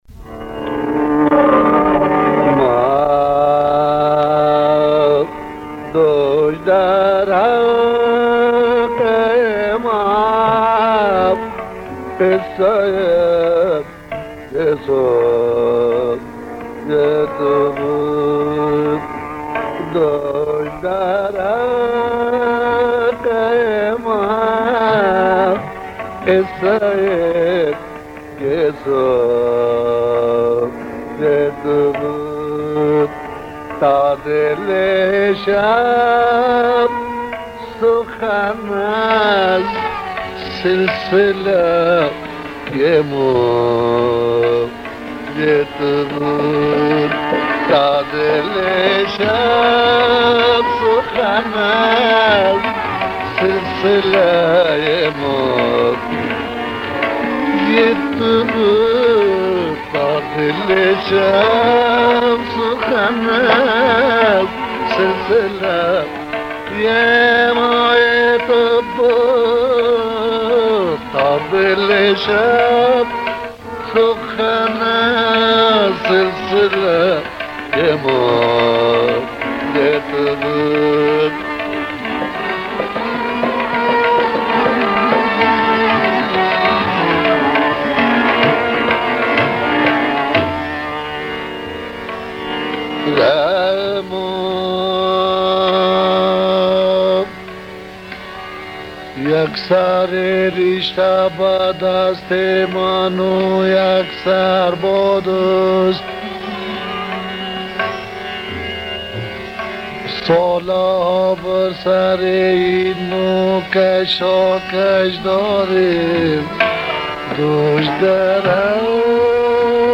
آهنگی است آرام و سنگین با شعر زیبا و بیت‌های شاهد مرتبط با شعر اصلی. یک نمونۀ کامل از سبک غزل‌خوانی استاد سرآهنگ.
آهنگ استاد سرآهنگ با شعر حیدری وجودی